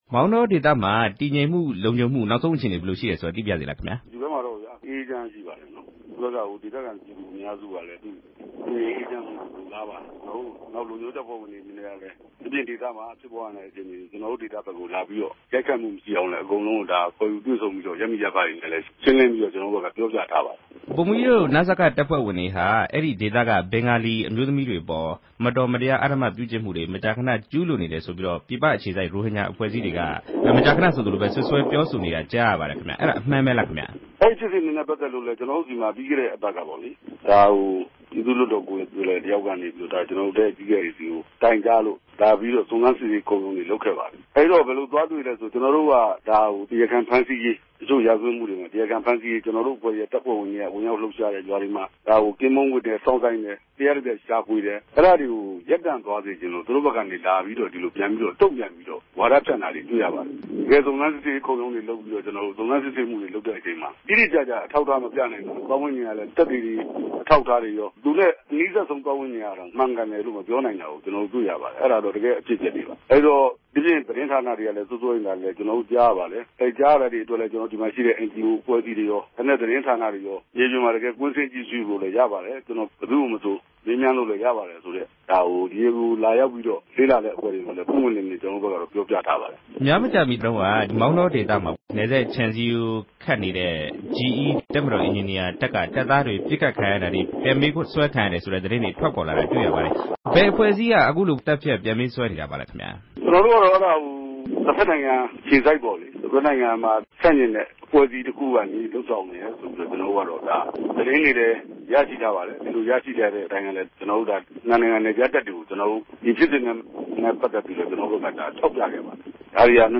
နစကတပ်ဖွဲ့ ကွပ်ကဲရေးမှူး ဗိုလ်မှူးကြီး အောင်နိုင်ဦးနဲ့ မေးမြန်းချက်